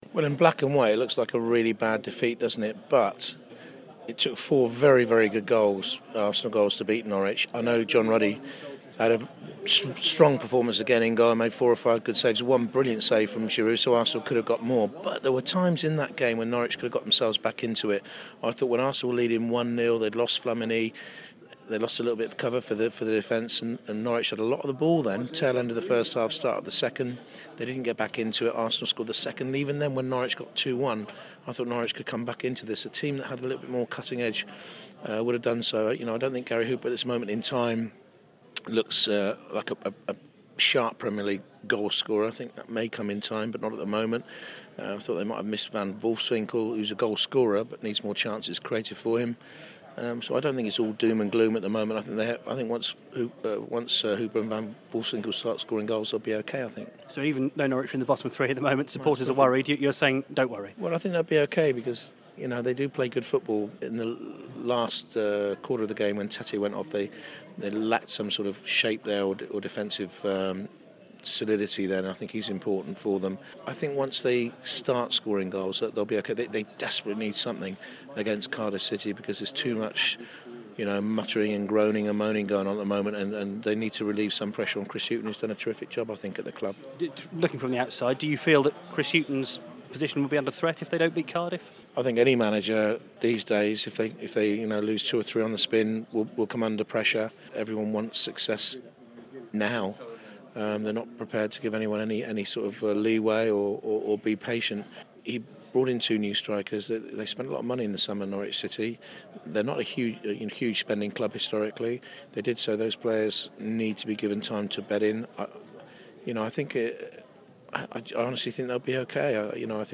Jonathan Pearce speaks to BBC Radio Norfolk after covering Arsenal 4-1 Norwich City for Match of the Day.